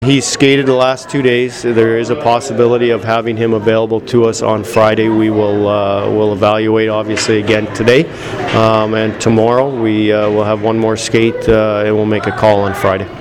I attended practice and talked with plenty of guys on the team.